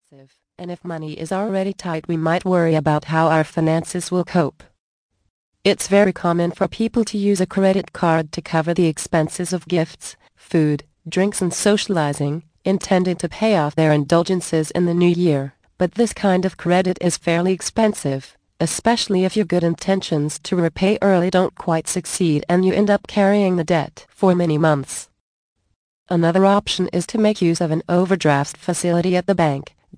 The Magic of Christmas audio book. Vol. 8 of 10 - 68 min.